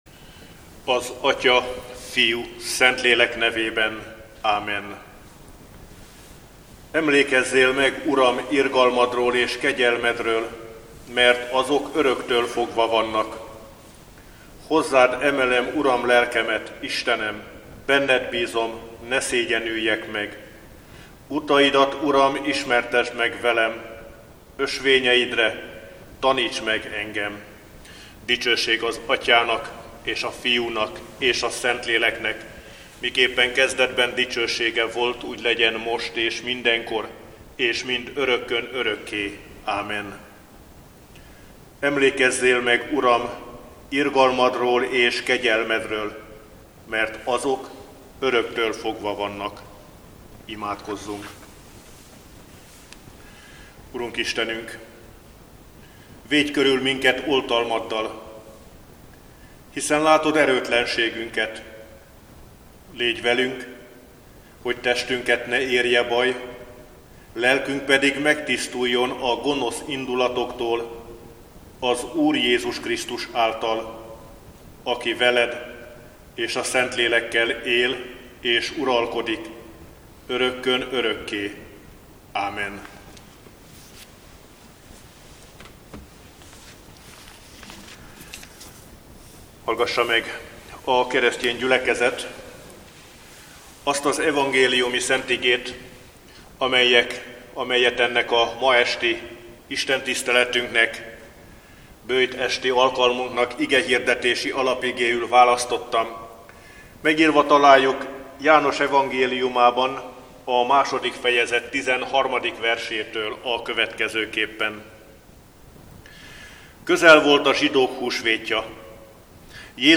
Böjt esti istentisztelet - Hiszen erre hívattatok el, mivel Krisztus is szenvedett értetek, és példát adott nektek, hogy az ő nyomdokait kövessétek.